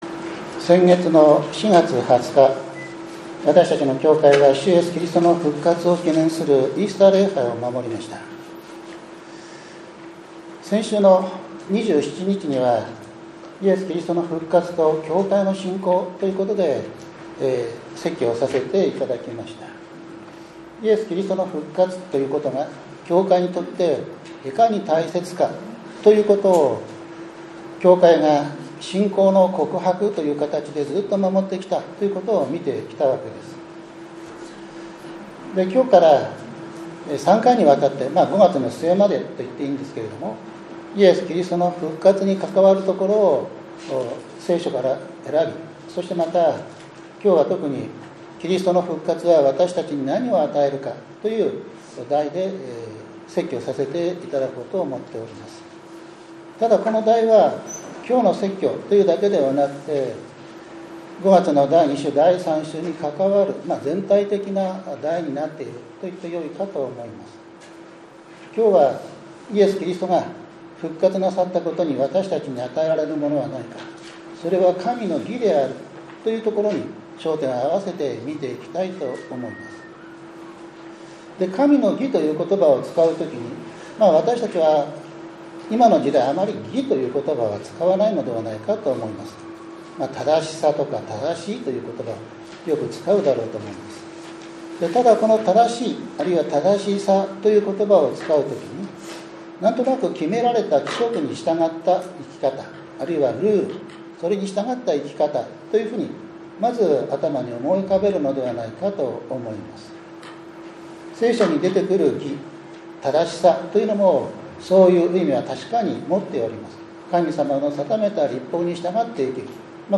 ５月４日（日）主日礼拝